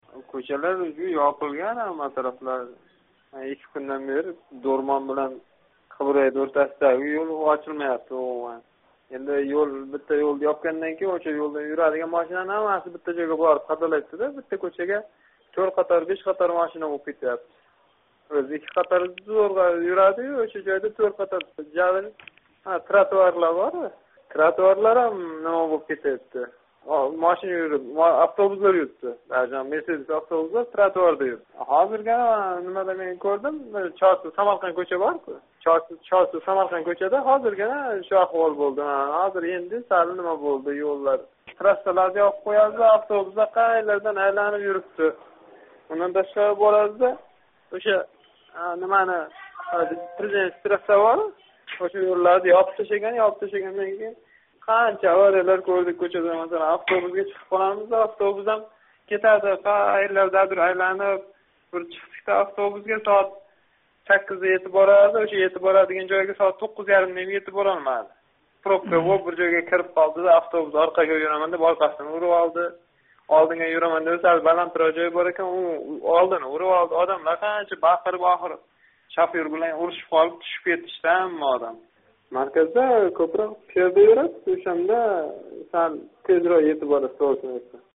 Тошкентлик ҳайдовчи: Одамлар машинасини қолдириб пиёда юрибди
Озодликка боғланган тошкентлик ҳайдовчи ШҲТ саммити боис Тошкентнинг марказий кўчалари ёпилгани, одамлар машиналарни қолдириб, пиёда юрганини айтди.